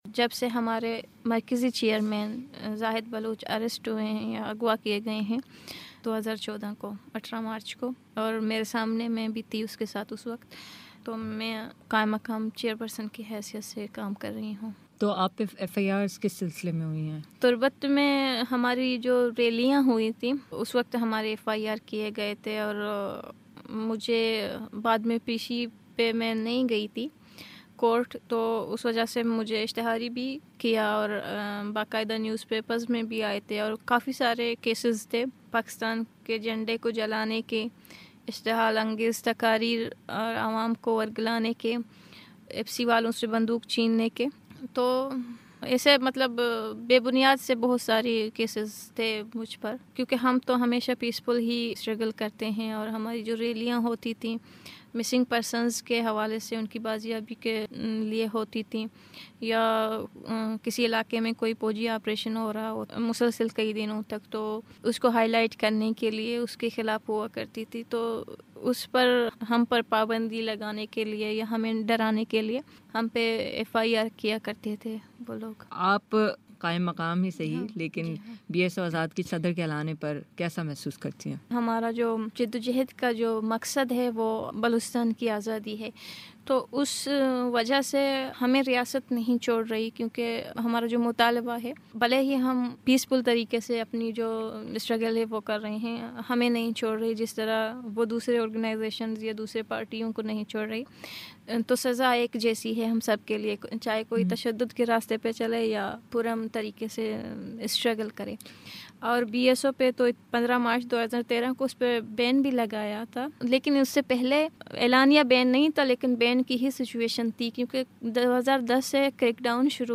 بلوچستان سٹوڈنٹ آرگنائزیشن (آزاد) کی پہلی خاتون سربراہ کریمہ بلوچ سے گفتگو